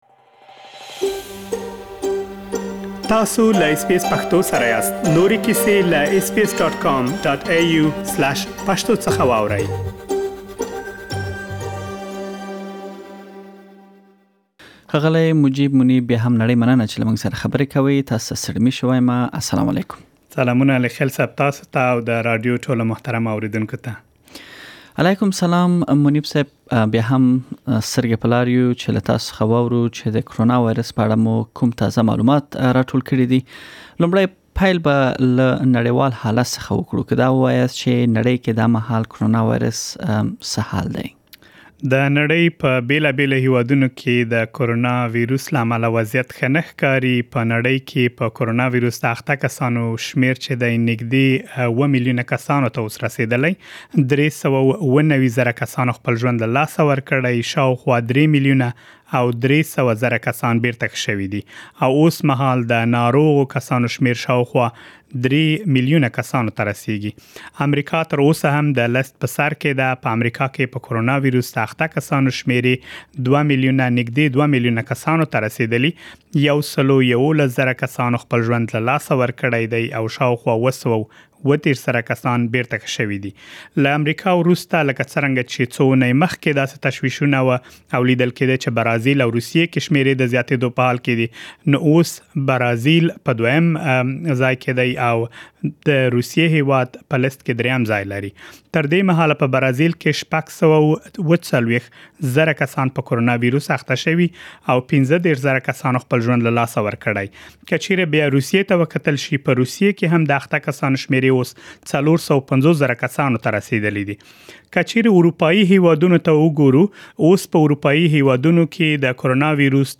د اسټراليا، افغانستان، پاکستان او د نړۍ نورو هيوادو تازه حال مو تاسو ته پدې رپوټ کې ځای کړی.